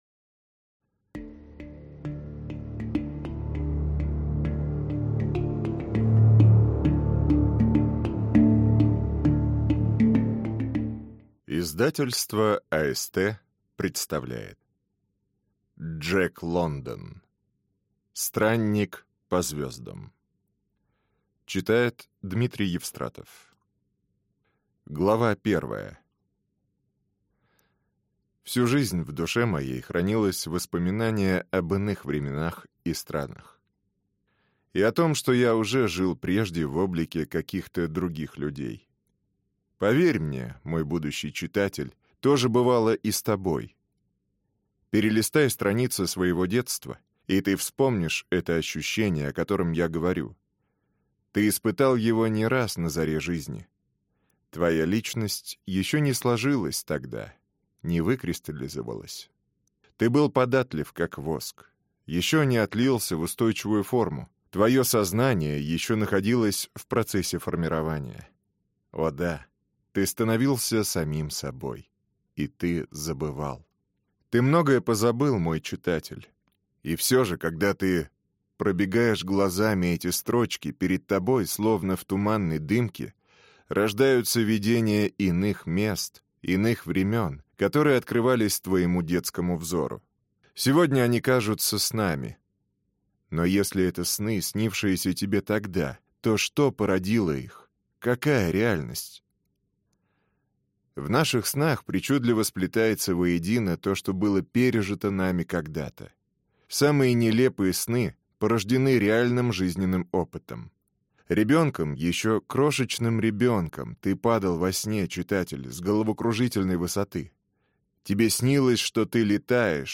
Аудиокнига Странник по звездам | Библиотека аудиокниг
Прослушать и бесплатно скачать фрагмент аудиокниги